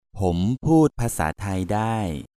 M